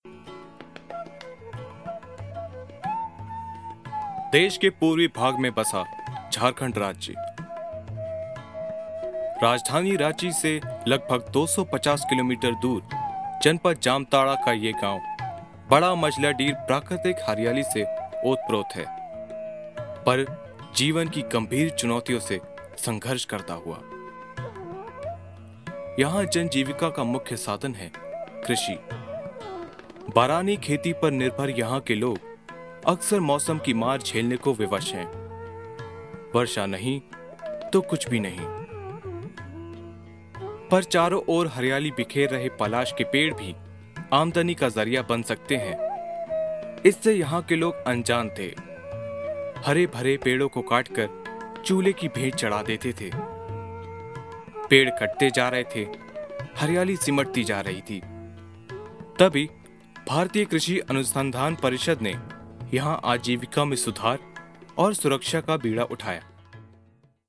Clear,young, mid range to low, friendly to authoritative voice. Good diction in both Hindi and English.
Sprechprobe: Sonstiges (Muttersprache):